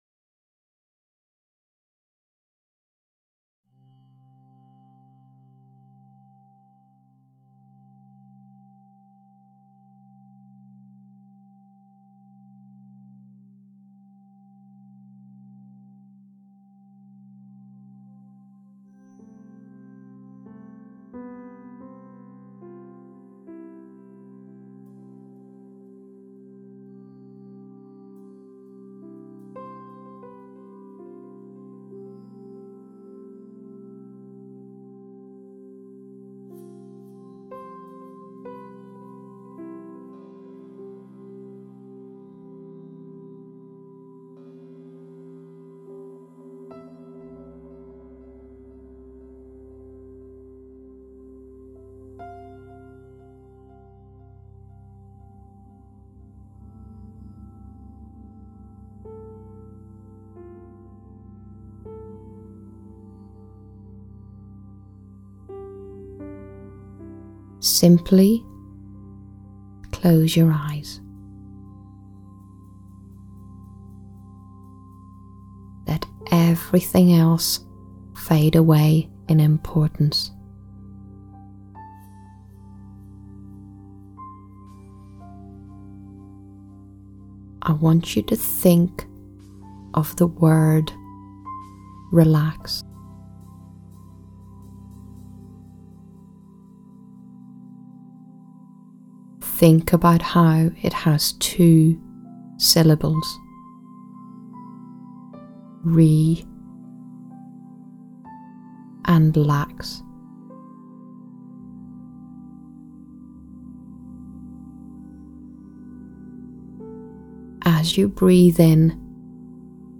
A hypnosis mp3 track designed to help establish the optimal 'diaphragmatic breathing' for promoting the hormonal environment for birth